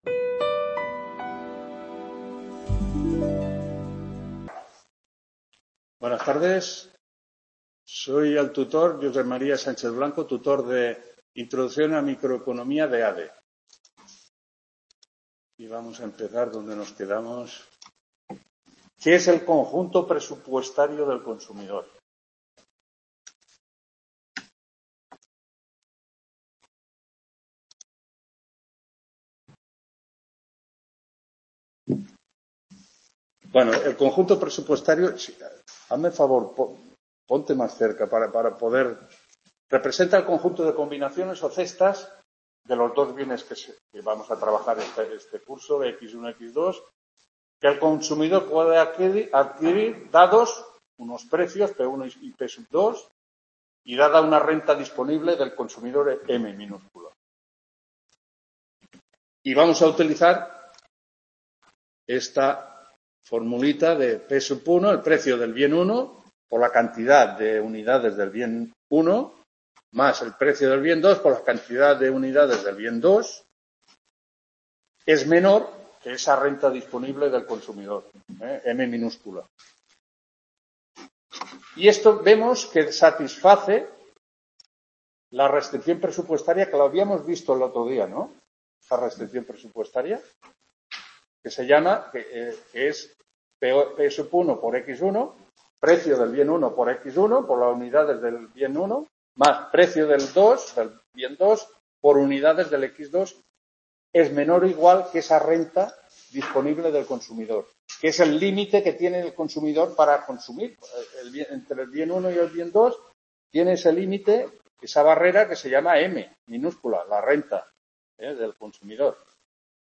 7ª TUTORÍA INTRODUCCIÓN A LA MICROECONOMÍA (ADE) 23-11… | Repositorio Digital